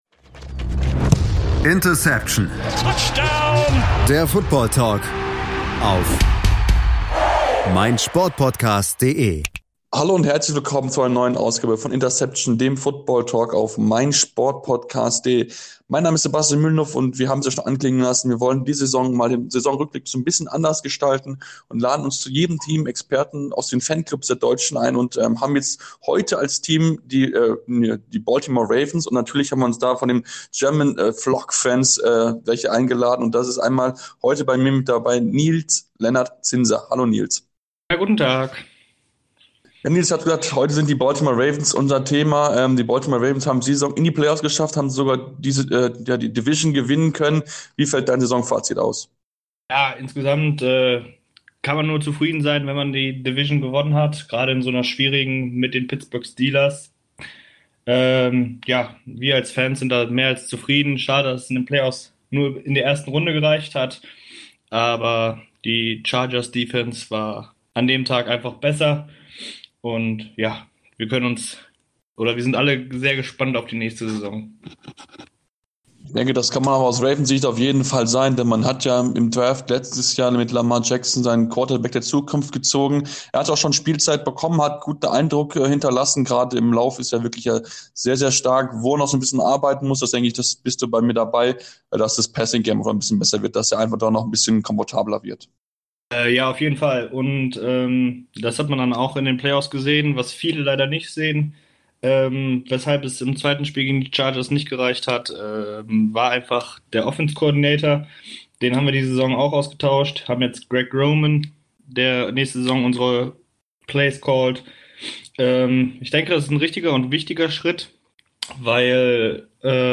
Es gibt eine Ausgabe zu jedem Team in der NFL, wo unsere Crew mit Fan-Experten über die jeweiligen Teams sprechen.